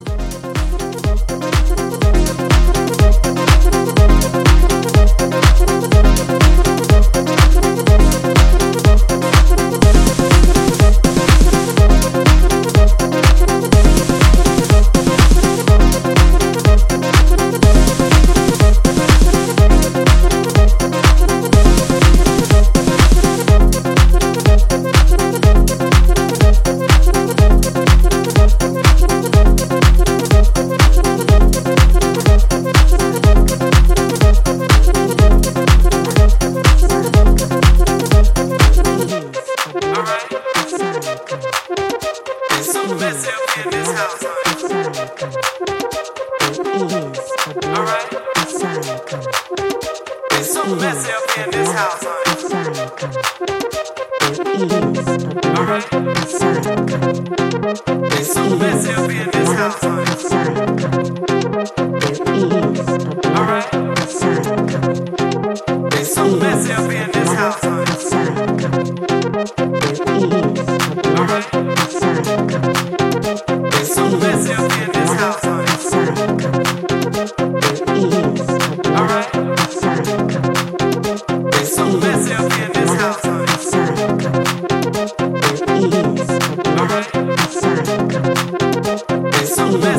重厚なボトムのピークタイム路線のハウスを展開するA-1。
ジャンル(スタイル) HOUSE